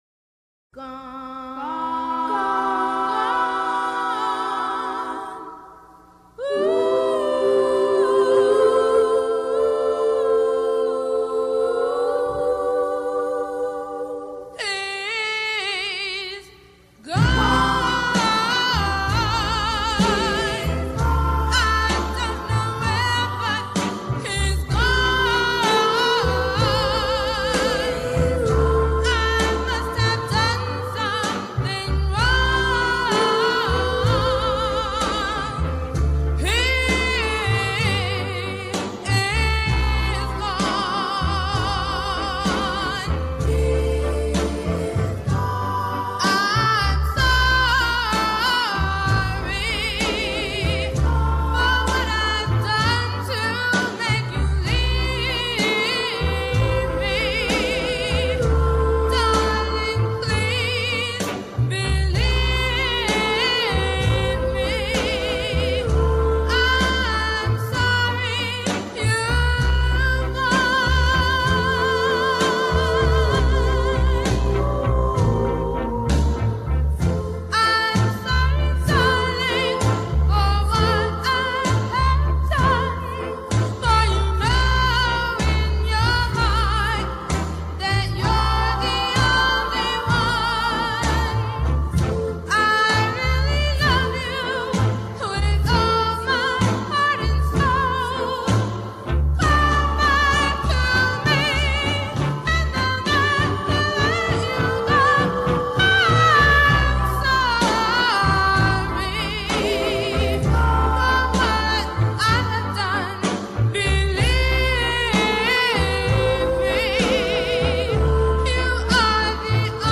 One of the first R&B “girl groups” to hit the charts